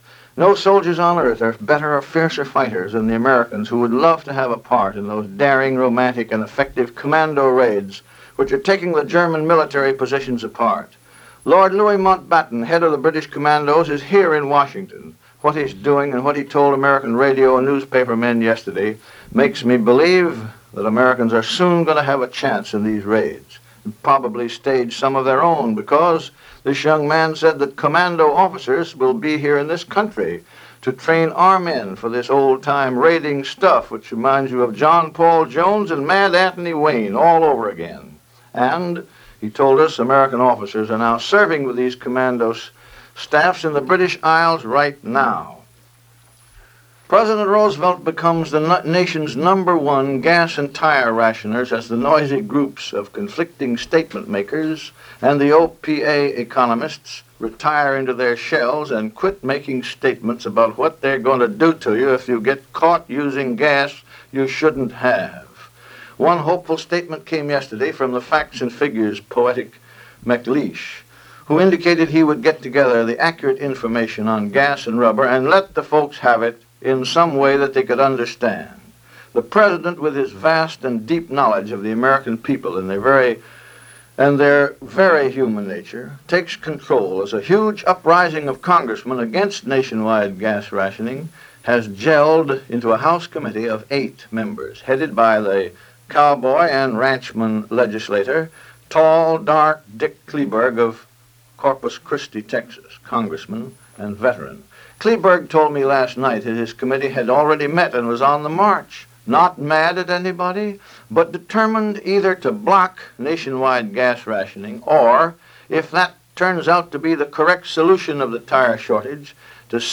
June 6, 1942 – owing to a badly damaged transcription disc, the first two minutes of this newscast are missing. But missing or not, the news was about the Battle Of Midway which was still going on as of this broadcast.